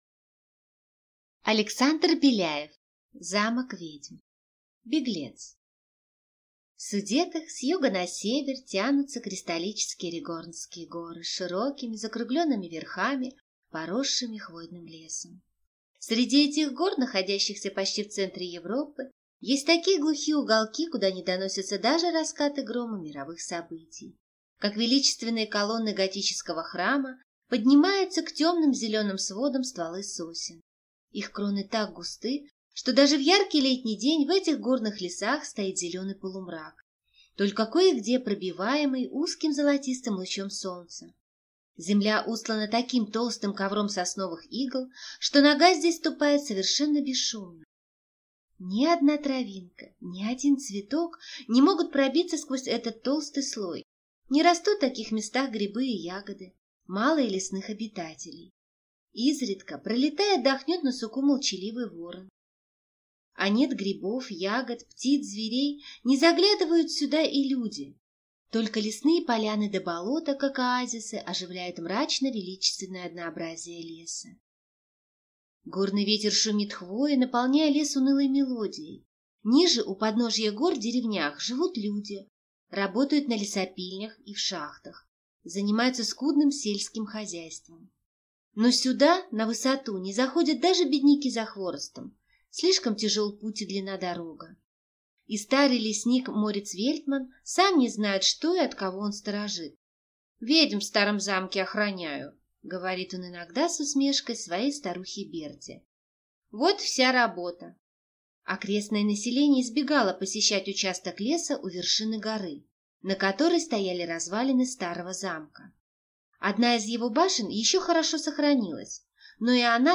Аудиокнига Замок ведьм | Библиотека аудиокниг